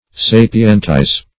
Sapientize \Sa"pi*ent*ize\, v. t.